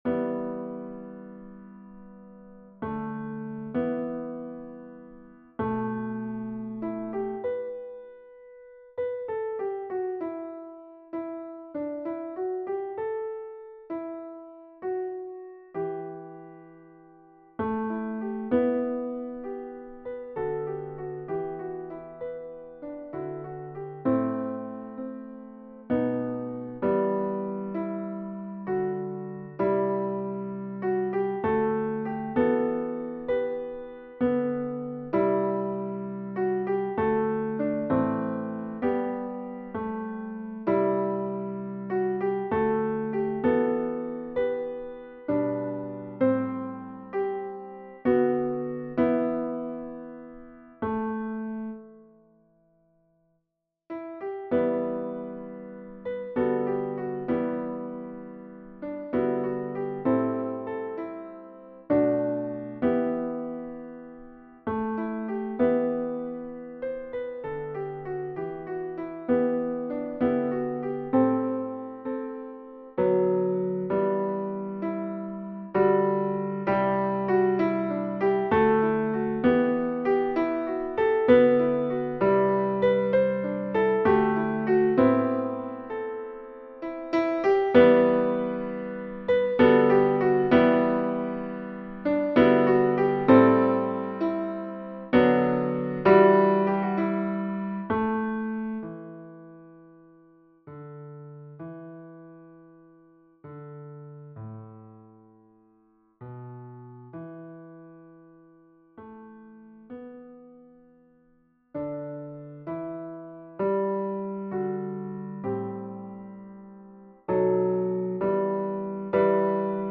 Versions "piano"
Tenor 1